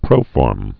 (prōfôrm)